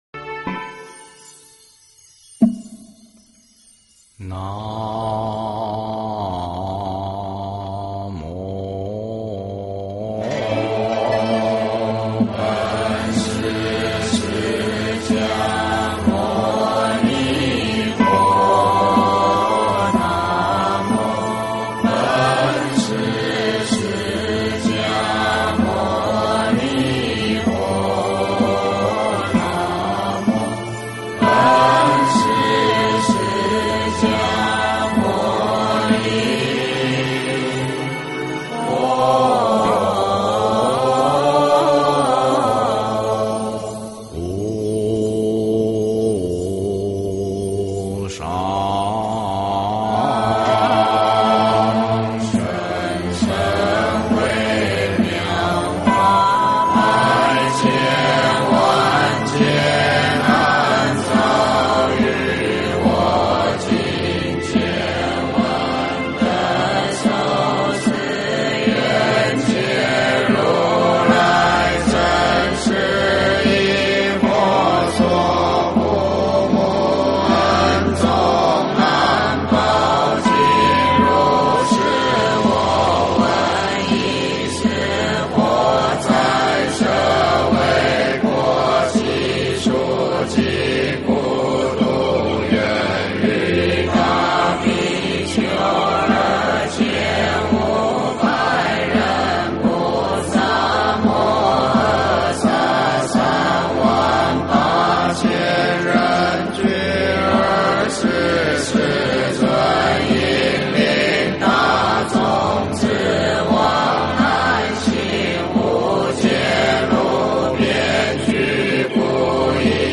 佛说父母重难报经 诵经 佛说父母重难报经--未知 点我： 标签: 佛音 诵经 佛教音乐 返回列表 上一篇： 佛说除一切疾病陀罗尼经 下一篇： 楞严咒快诵 相关文章 Asian Rhapsody亚洲狂想曲--禅定音乐 Asian Rhapsody亚洲狂想曲--禅定音乐...